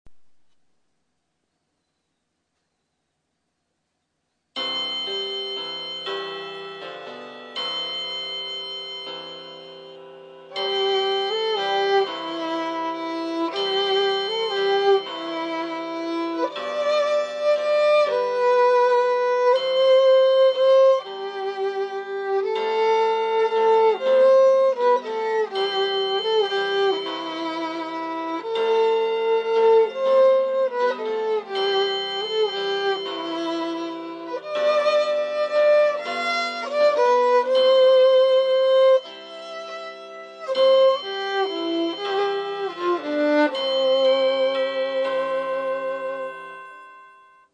ちなみに去年のﾚｽﾄﾗﾝ余興前に録音したのもひょっこり出てきたので、ついでにｱｯﾌﾟ。
Pf他…自作PC音源